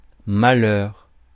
Ääntäminen
US : IPA : [ˈsæd.nɛs]